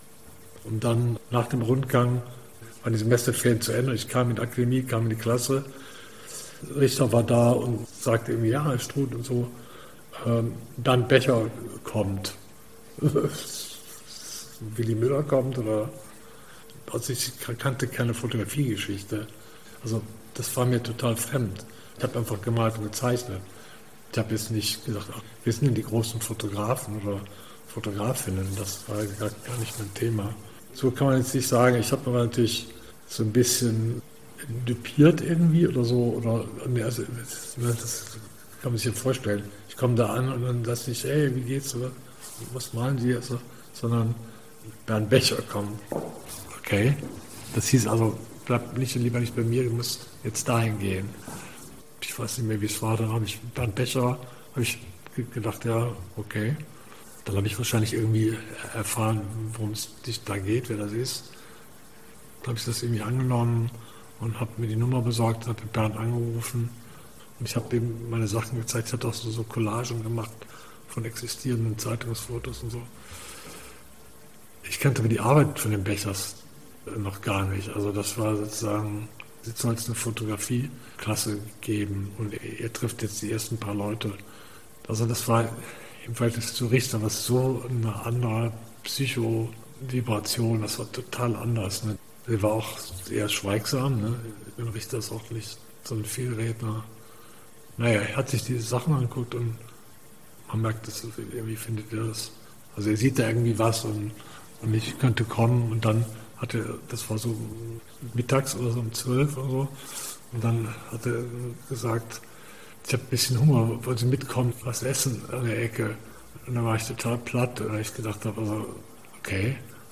Interview Audioarchiv Kunst: Thomas Struth über den Wechsel in die Klasse Bernd Becher